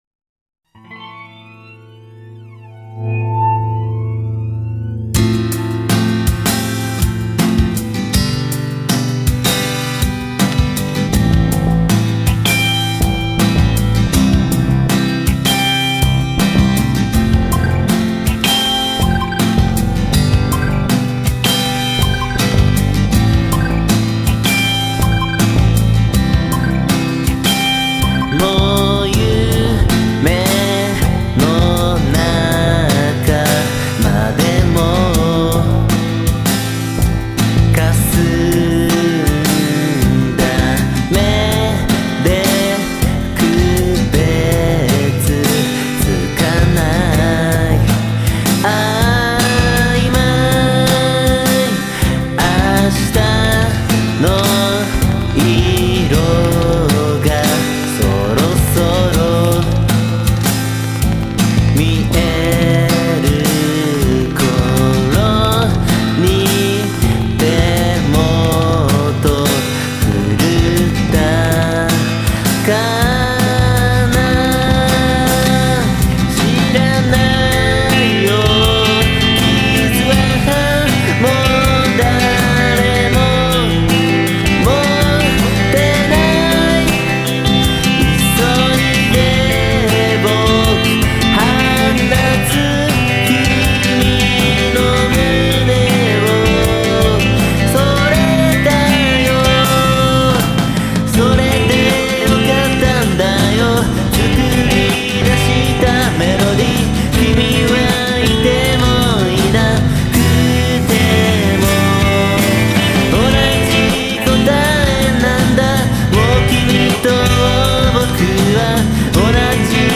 ユックリト　セツナイイタイウタ